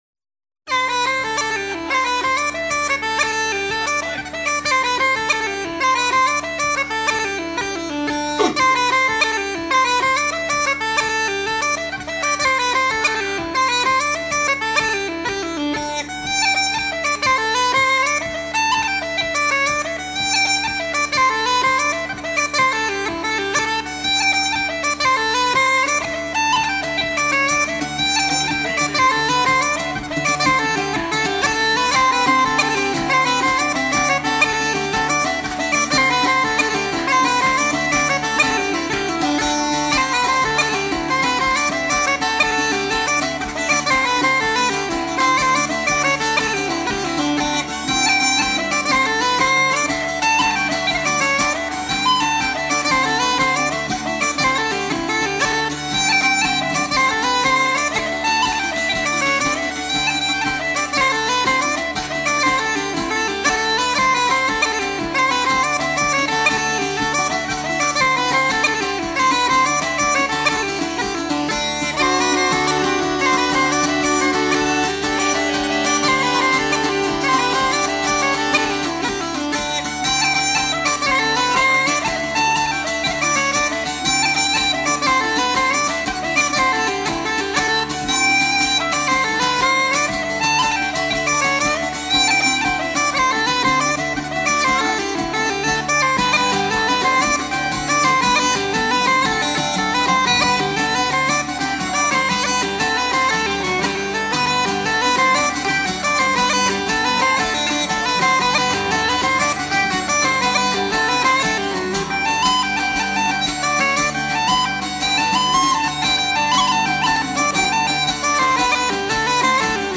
musiciens réunis le temps d'un concert
Pièce musicale éditée